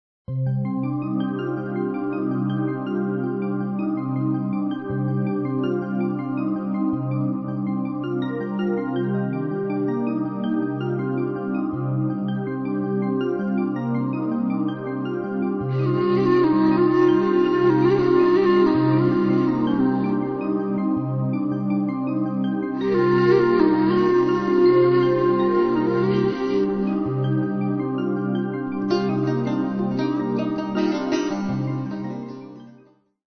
Relaxation, Musicothérapie